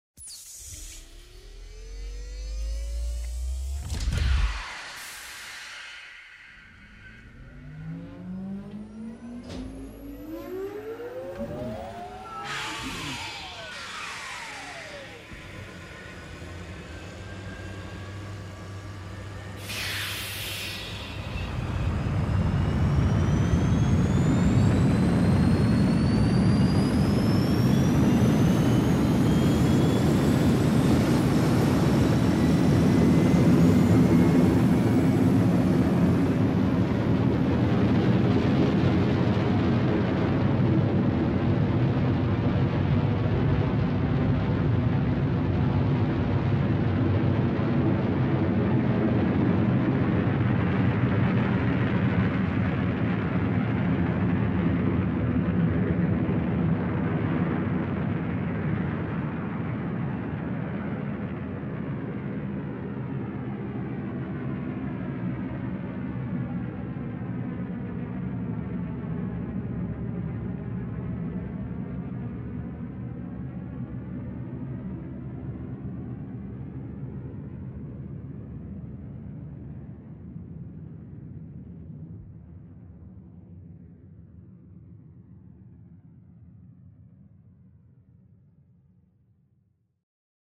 Полет космического корабля ввысь